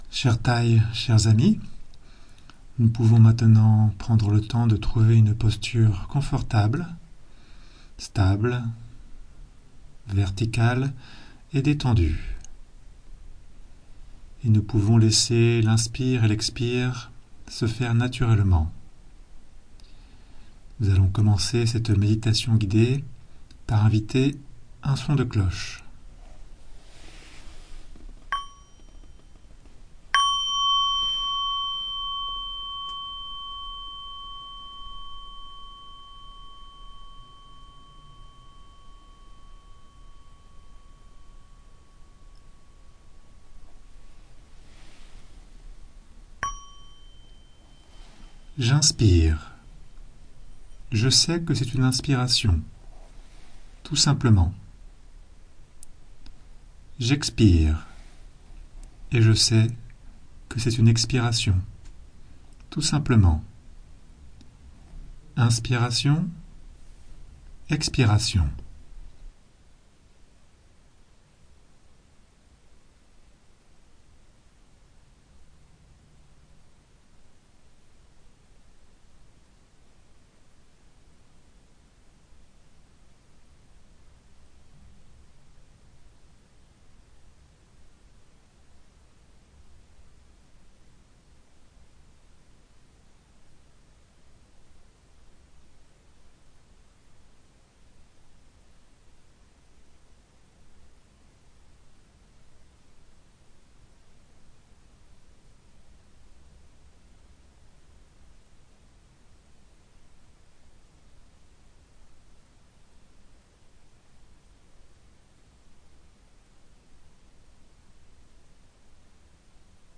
Témoignage / Méditation / Méditation Guidée du « Retour au Royaume » | Village des Pruniers